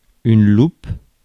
Ääntäminen
France: IPA: /lup/